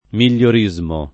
migliorismo [ mil’l’or &@ mo ]